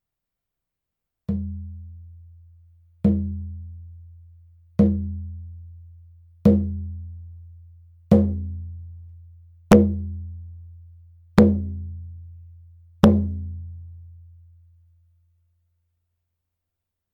フレームドラム　ネイティブアメリカン（インディアン）スタイル
素材：牛革・天然木
パキスタン製フレームドラム 音